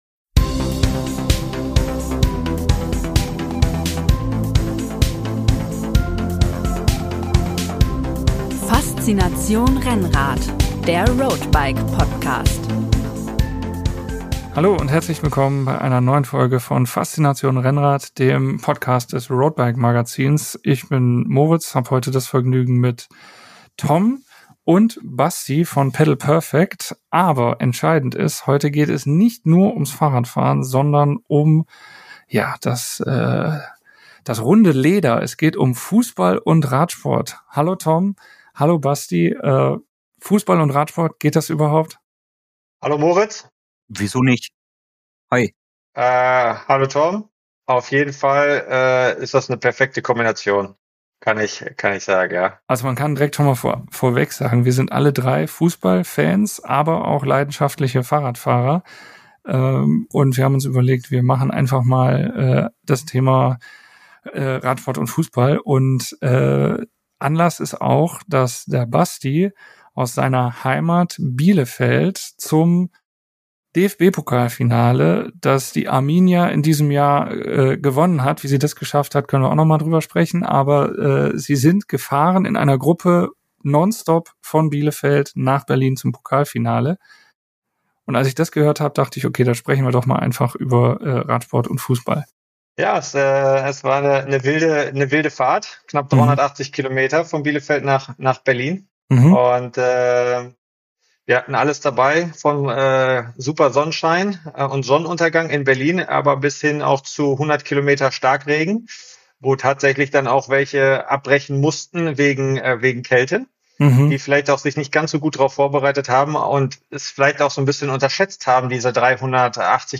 Unser erster Podcast vor Publikum! Was macht Tour-Etappensieger Simon Geschke sechs Monate nach seinem Karriereende? Wie führt man eine Gruppe, wie gibt man wertschätzend Tipps?